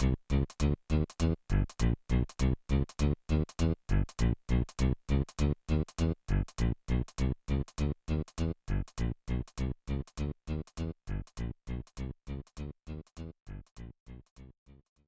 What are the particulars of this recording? Fadeout applied